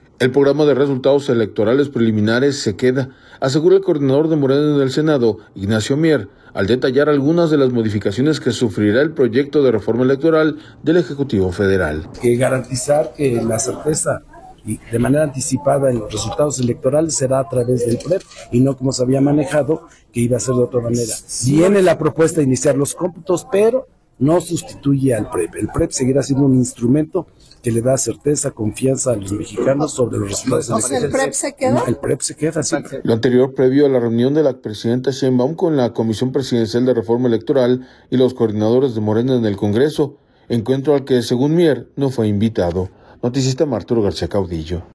El Programa de Resultados Electorales Preliminares se queda, asegura el coordinador de Morena en el Senado, Ignacio Mier, al detallar algunas de las modificaciones que sufrirá el proyecto de reforma electoral del Ejecutivo Federal.